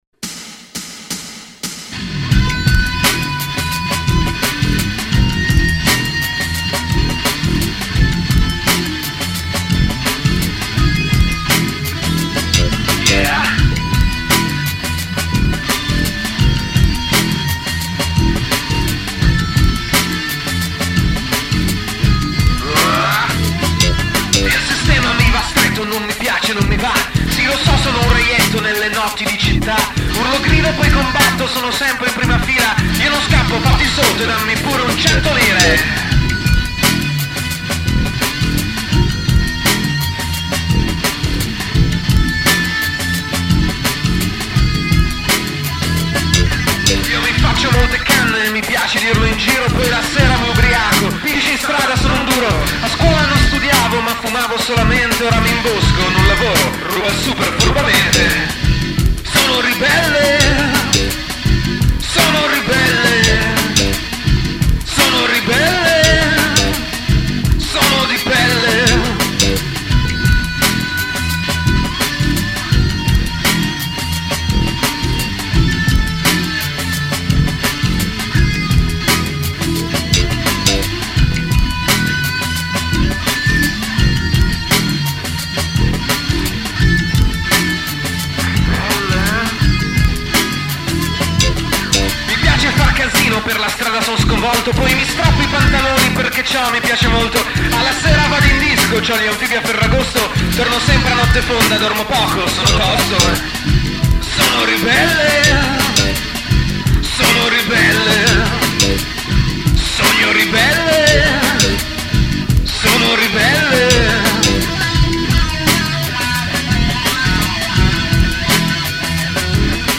Basso
Chitarre e Programming